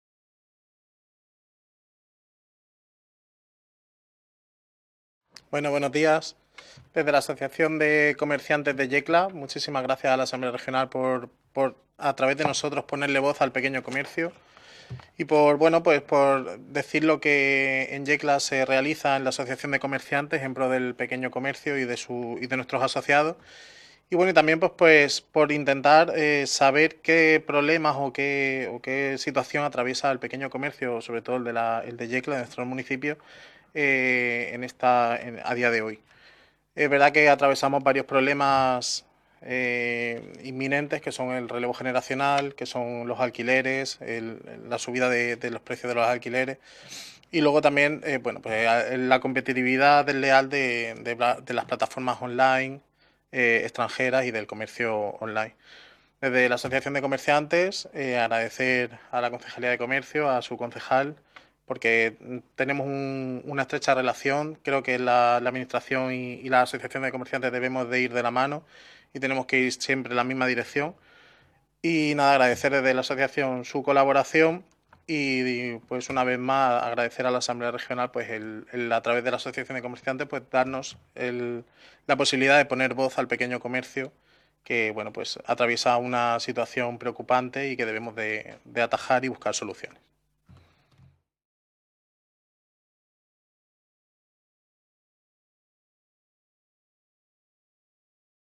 Ruedas de prensa tras la Comisión Especial de Estudio sobre el Pequeño Comercio en la Región de Murcia